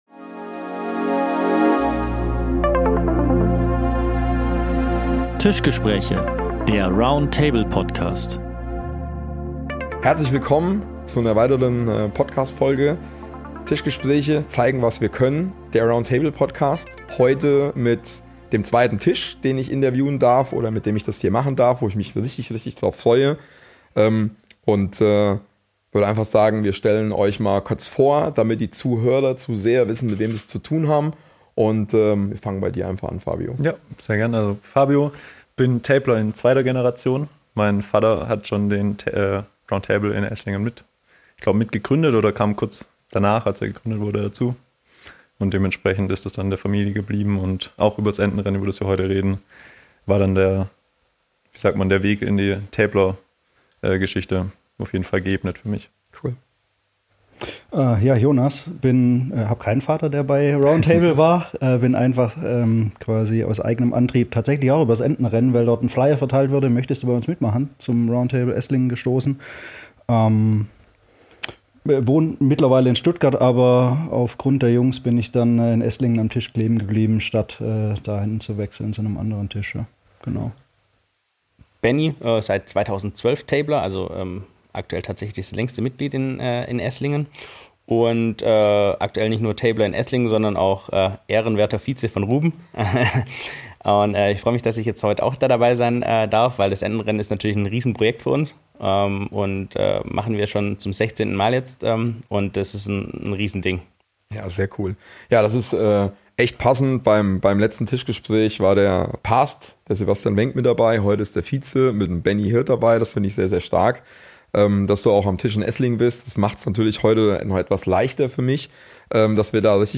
Dieses Mal sind die Tabler aus Esslingen im Gespräch.